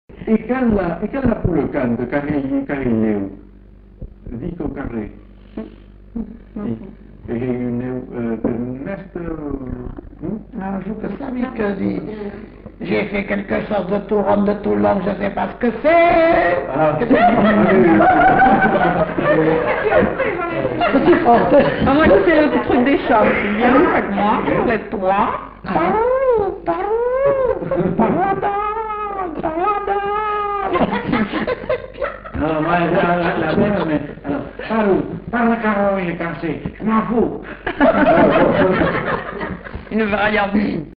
Aire culturelle : Bazadais
Lieu : Villandraut
Genre : forme brève
Type de voix : voix de femme
Production du son : récité
Classification : mimologisme